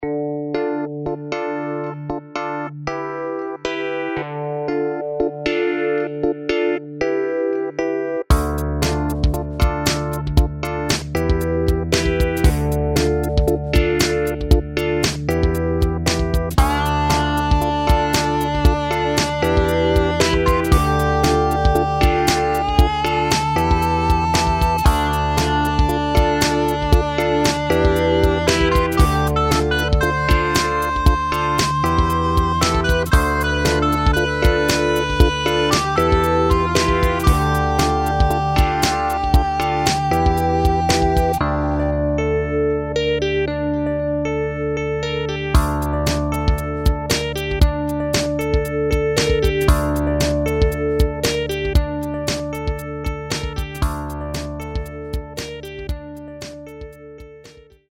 DX7 EPiano - 130mbDecember, 2007
The famous DX7 Electric Piano Sound is captured faithfully in this large soundset.
All other sounds are also from the upcoming "FM-Classic Synth" sample pack, and all of the sounds are played using ManyBass.
Manytone_DXTX_Demo1edit.mp3